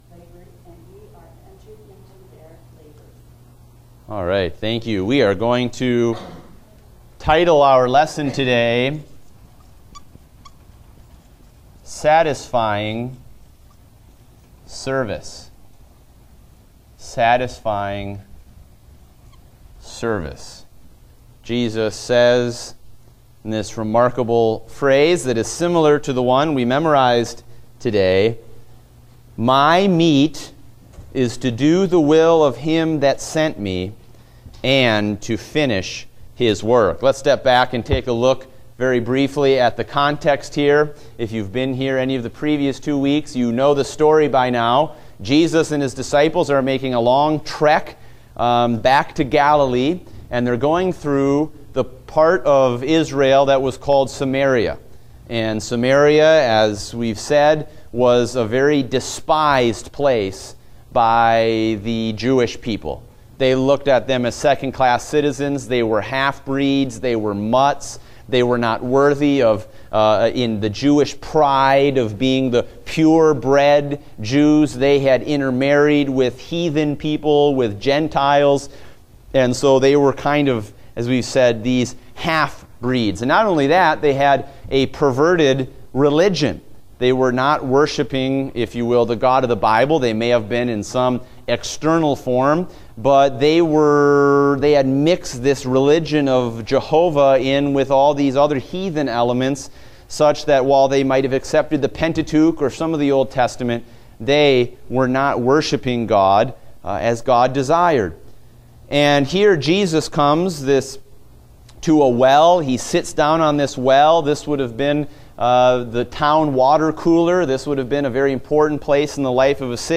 Date: June 26, 2016 (Adult Sunday School)